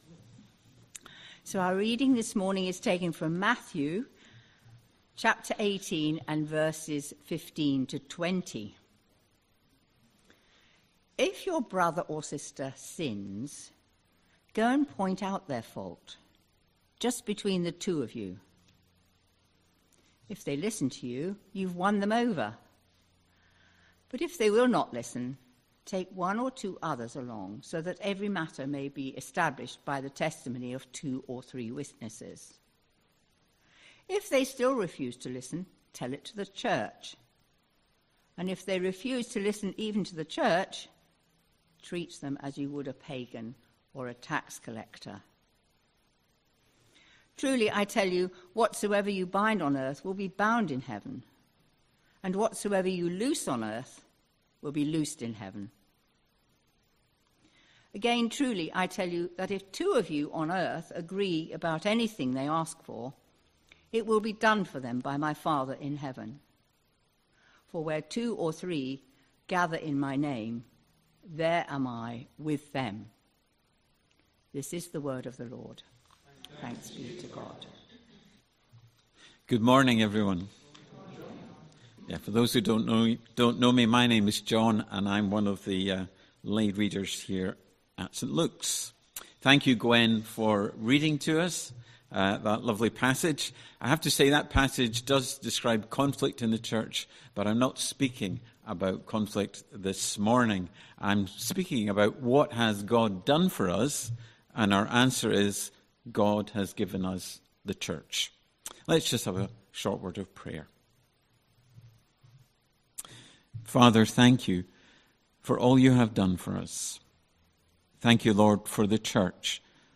16th November 2025 Sunday Reading and Talk - St Luke's